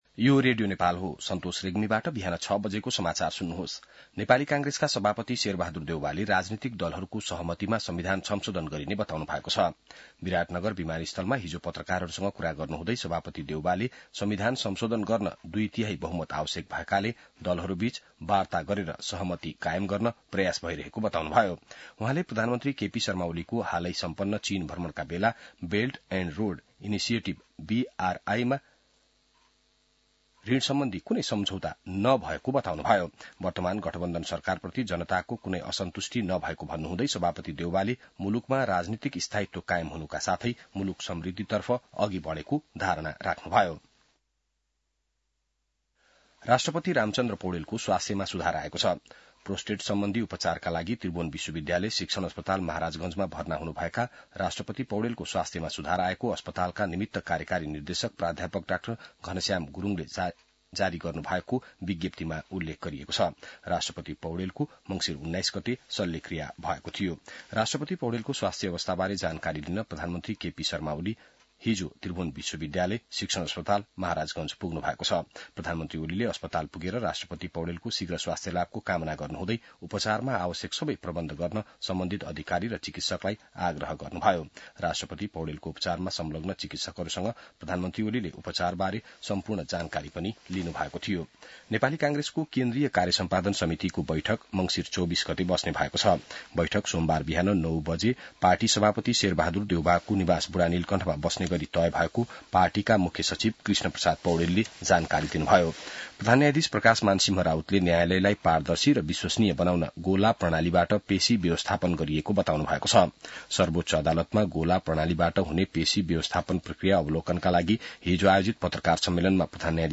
An online outlet of Nepal's national radio broadcaster
बिहान ६ बजेको नेपाली समाचार : २३ मंसिर , २०८१